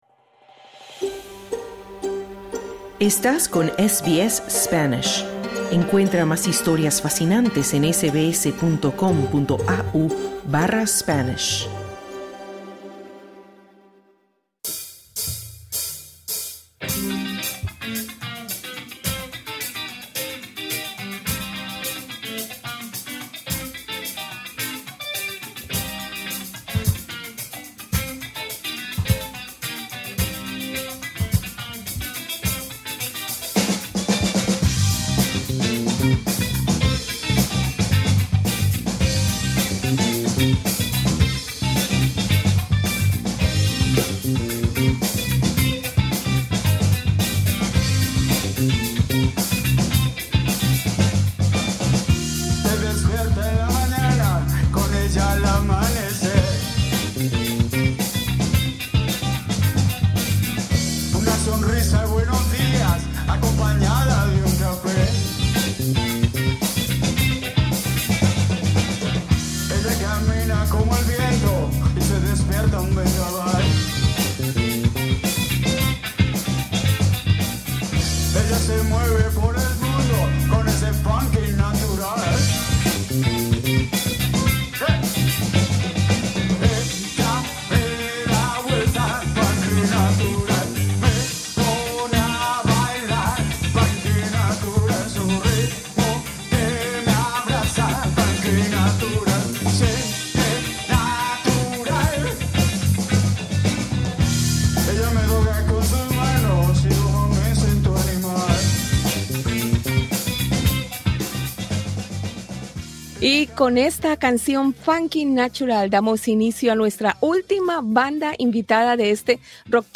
banda de rock basada en Adelaide.